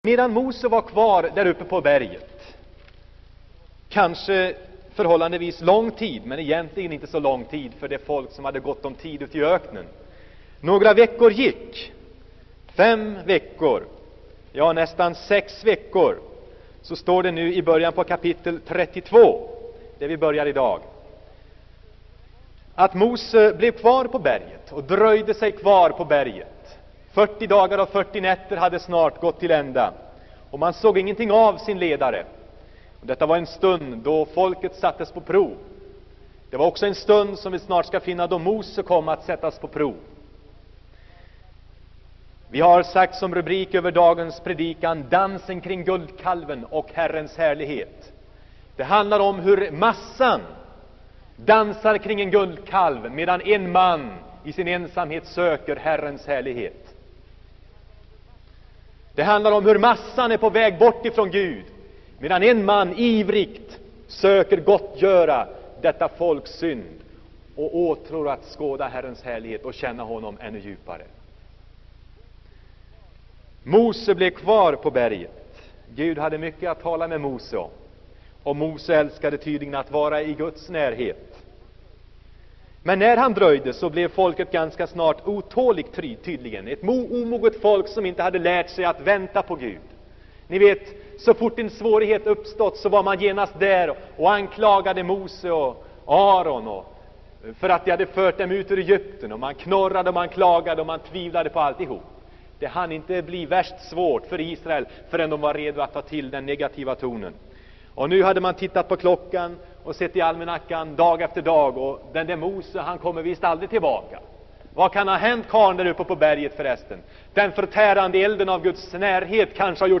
Inspelad i Saronkyrkan, Göteborg 1976-09-25.